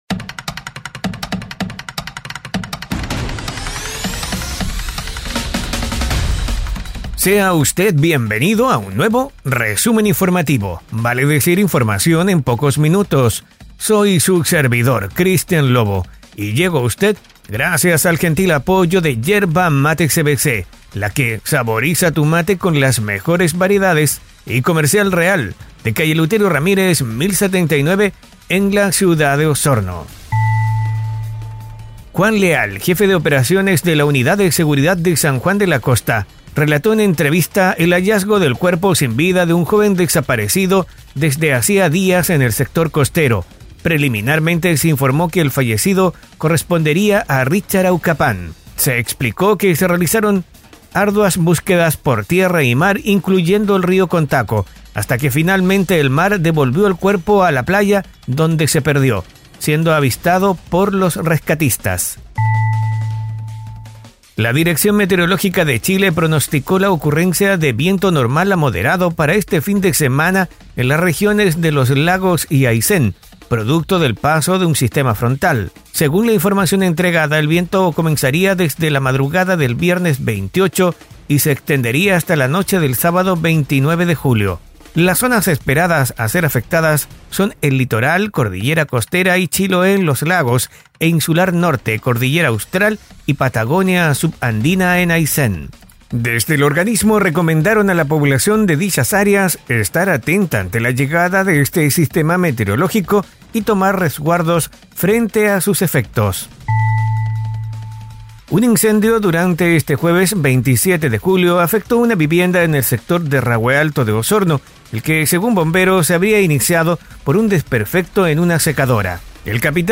🎙📰 ¡Bienvenidos a Resumen Informativo! Este audio podcast te trae un resumen rápido y conciso de una decena de noticias enfocadas en la Región de Los Lagos.